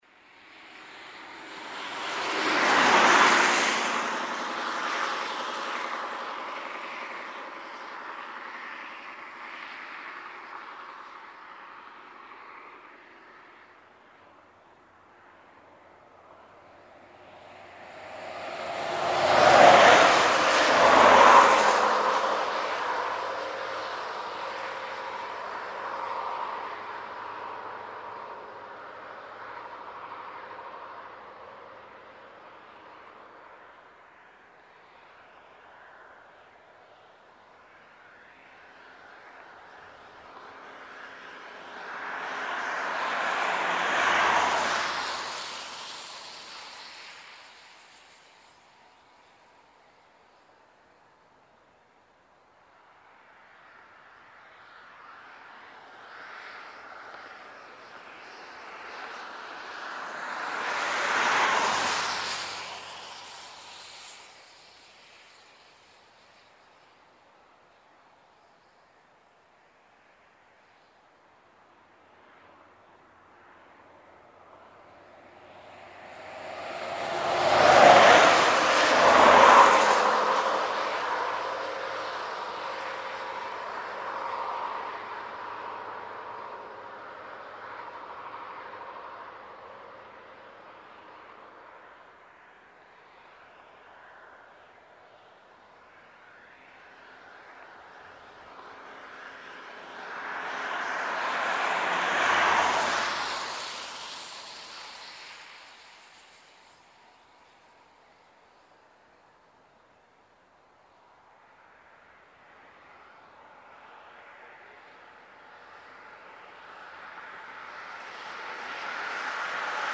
Trafic pe autostradă, drum umed: